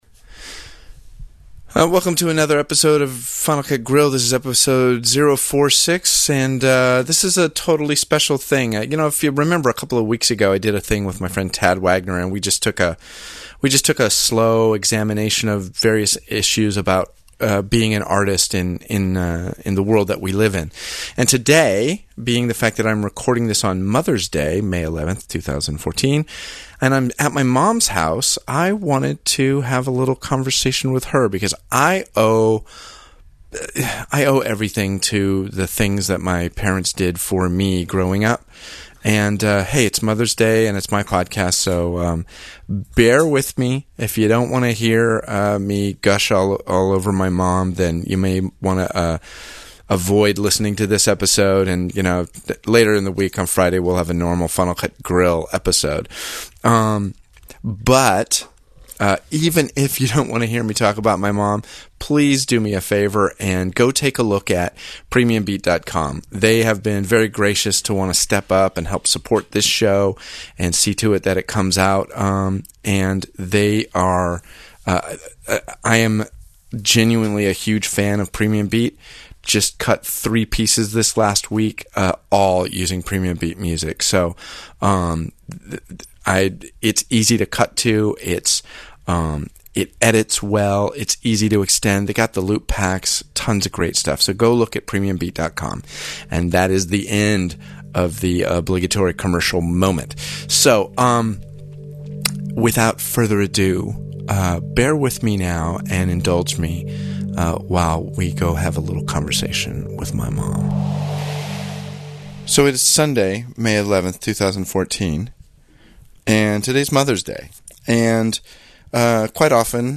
I decided that instead of NOT having a show this week because of Mothers Day that I’d go off the path a bit and talk about art with the first artist I ever knew in my life. My Mom, I think you’ll enjoy this chat.